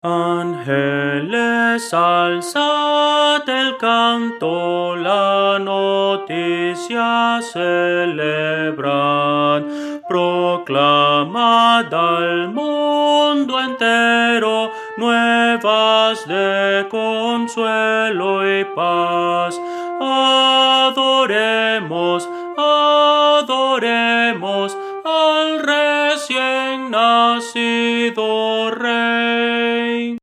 Voces para coro
Soprano – Descargar